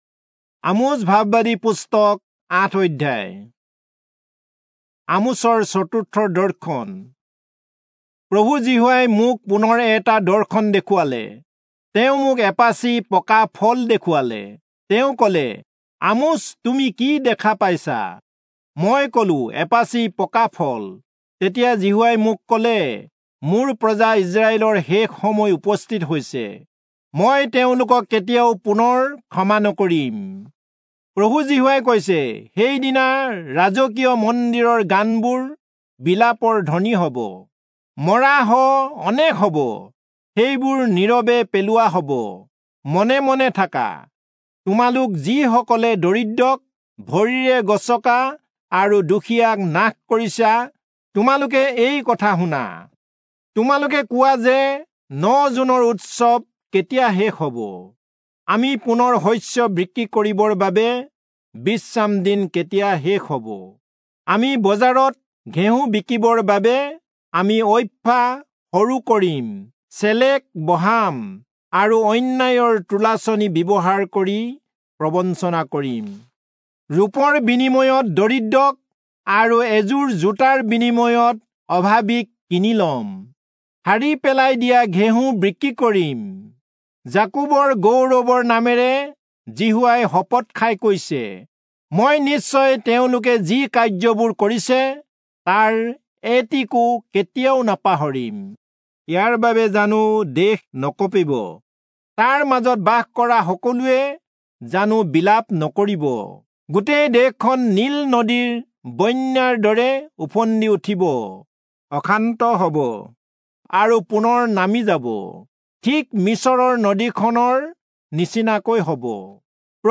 Assamese Audio Bible - Amos 2 in Irvta bible version